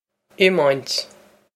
Pronunciation for how to say
im-oint
This is an approximate phonetic pronunciation of the phrase.